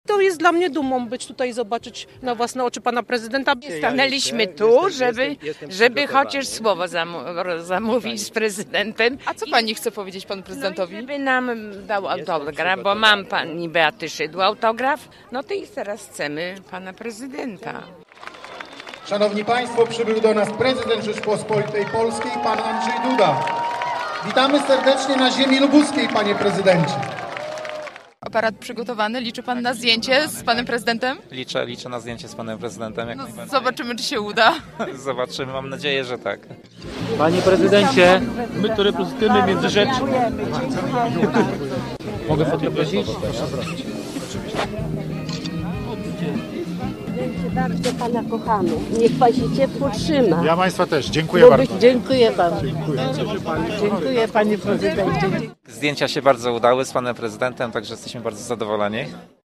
Andrzej Duda ściskał dłonie, nie odmawiał zdjęć i chętnie odpowiadał na pytania mieszkańców miasta i regionu uczestniczących w spotkaniu na placu Grunwaldzkim